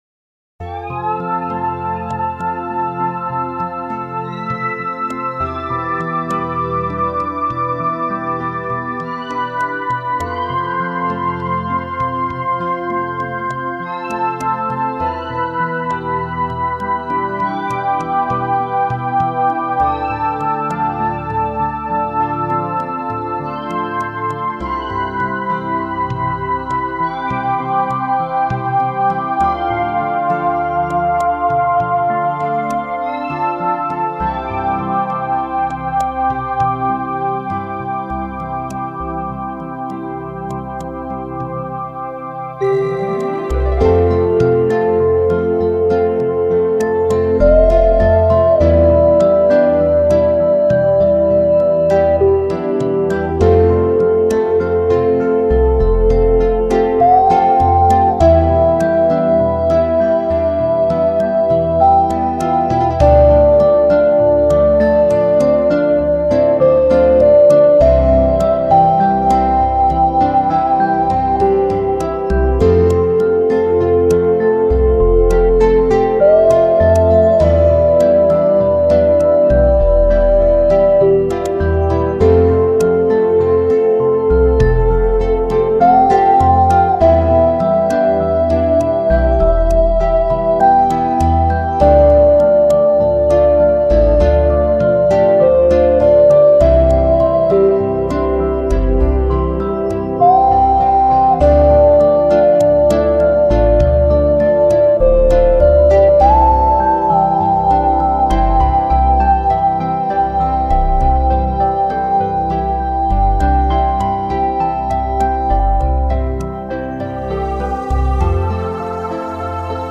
專家嚴選．對症下樂：閒適、紓緩的節奏帶來平和的心靈步調，適度的安撫交感神經，讓身心得到放鬆呼吸的愉悅感。